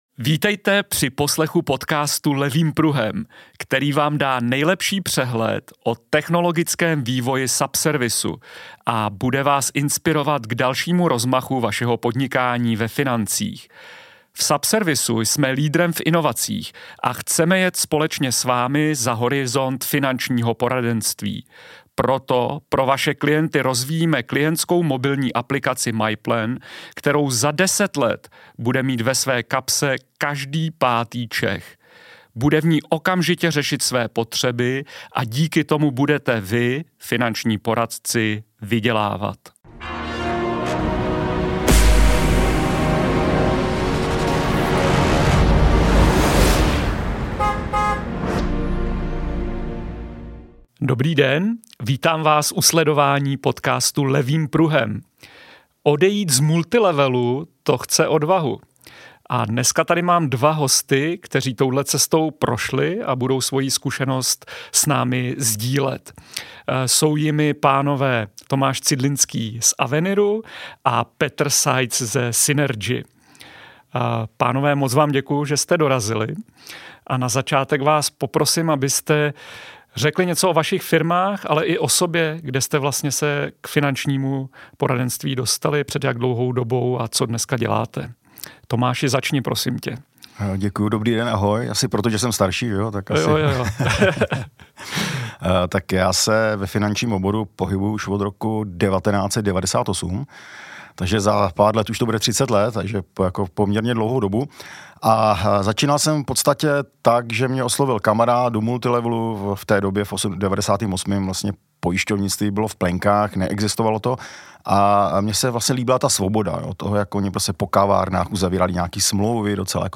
Přinášíme inspirativní rozhovor se dvěma lídry, kteří takovou transformaci zvládli.